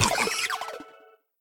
Cri de Fragroin femelle dans Pokémon HOME.